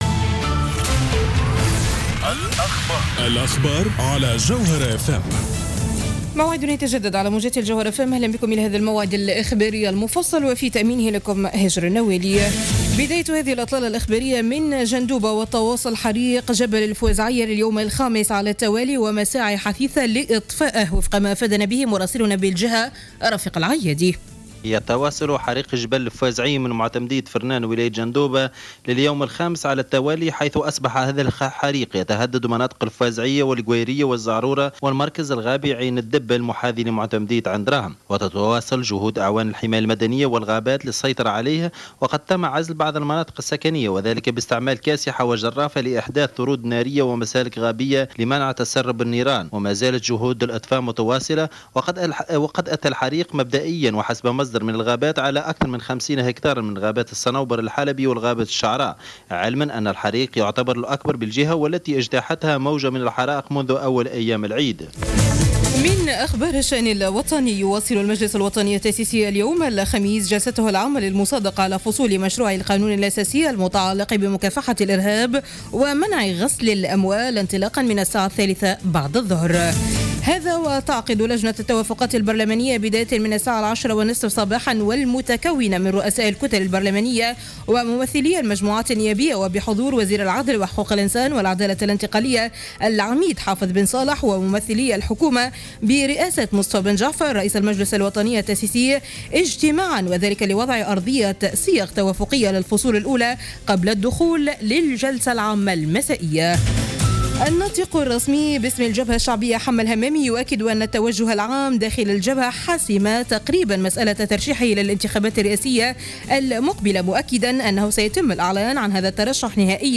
نشرة أخبار منتصف الليل ليوم الخميس 14-08-14